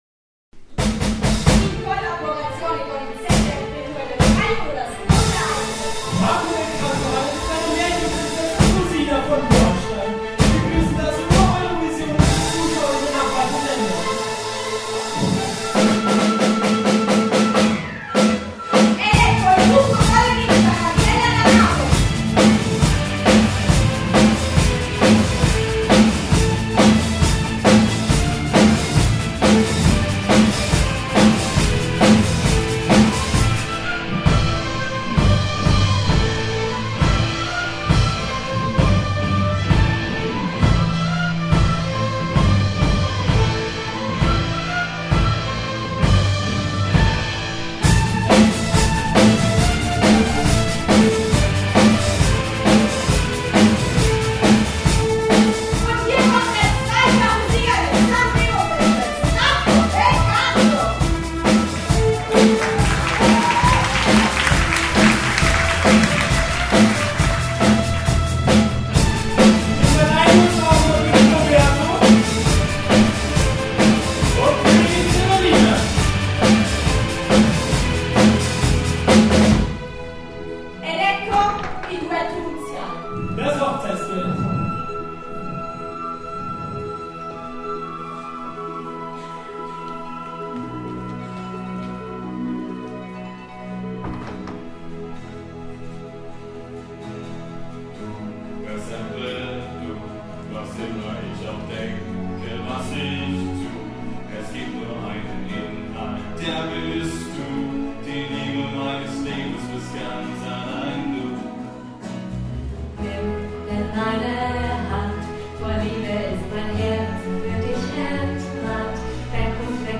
Hochzeitsduett
hochzeitsduett.mp3